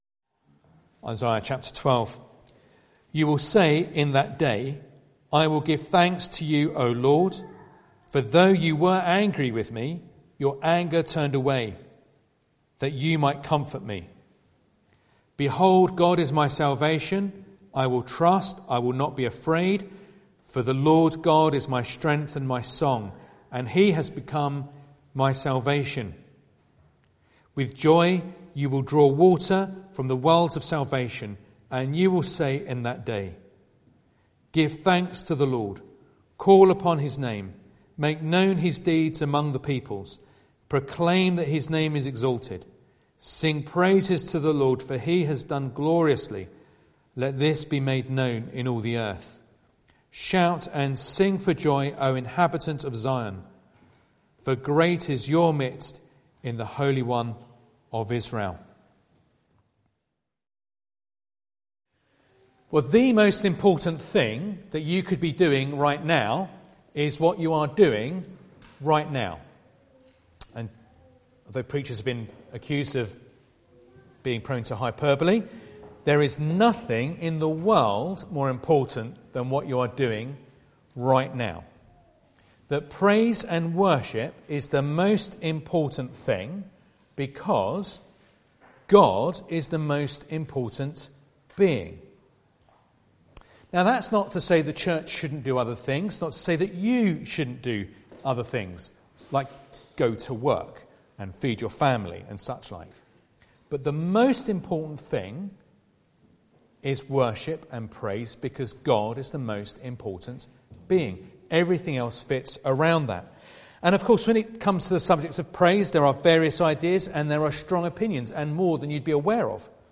Passage: Isaiah 12 Service Type: Sunday Morning